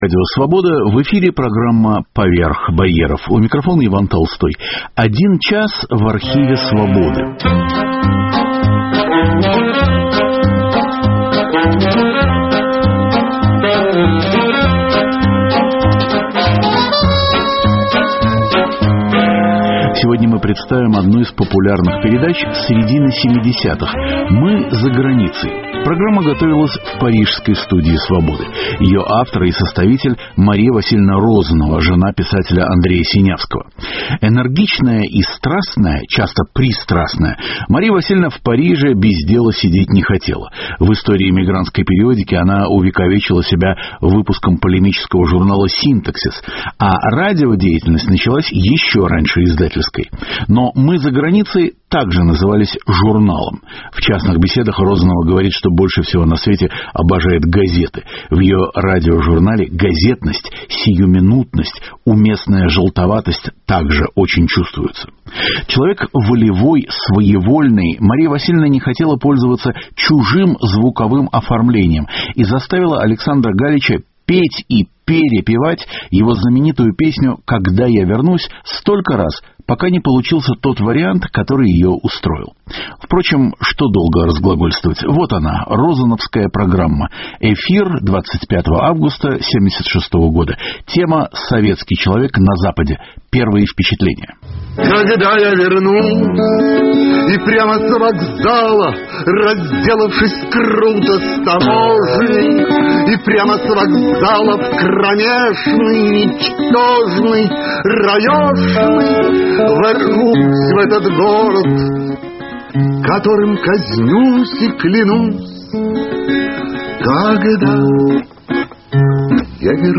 В середине 1970-х годов в парижской студии нашего радио записывались программы "Мы заграницей".